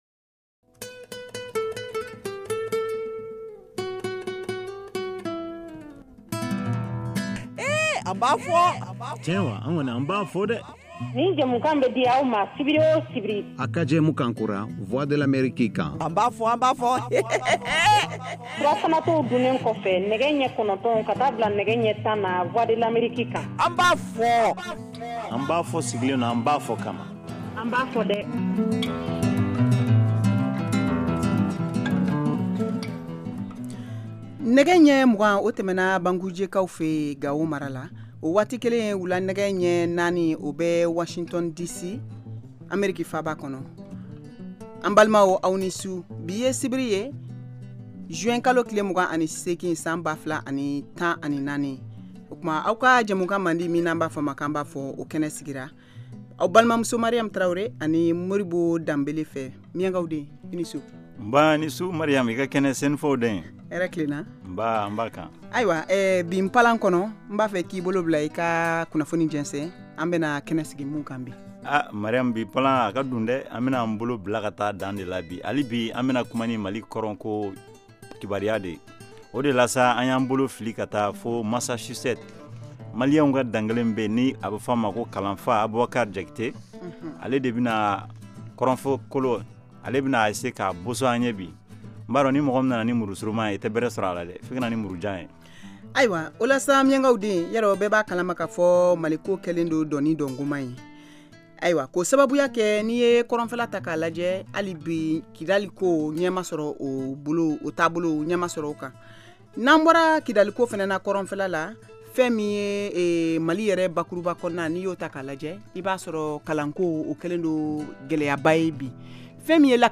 An ba fɔ! est une nouvelle émission interactive en Bambara diffusée en direct tous les samedis, de 20:00 à 21:00 T.U.